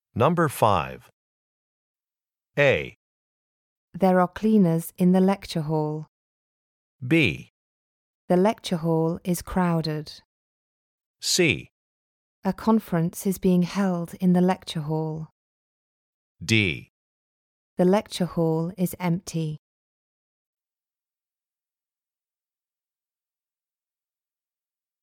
For each question in this part, you will hear four statements about a picture in your test book.
The statements will not be printed in your test book and will be spoken only one time.